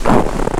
High Quality Footsteps
STEPS Snow, Walk 27-dithered.wav